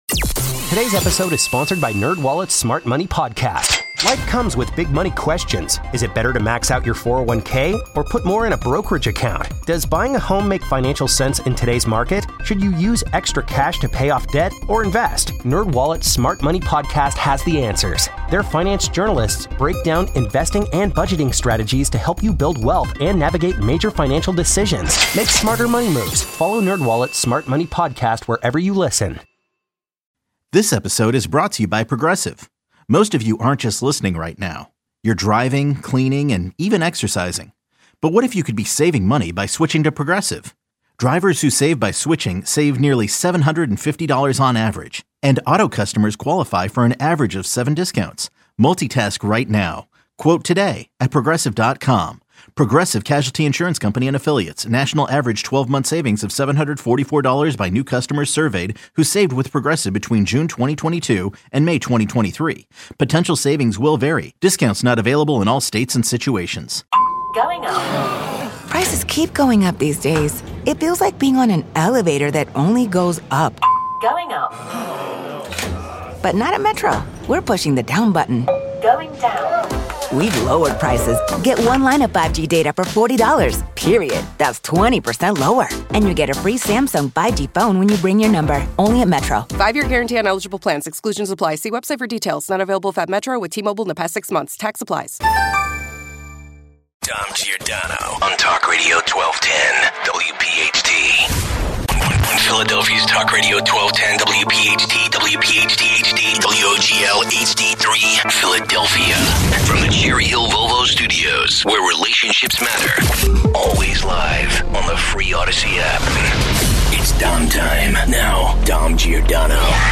Dom will not let up on this issue. 235 - Your calls.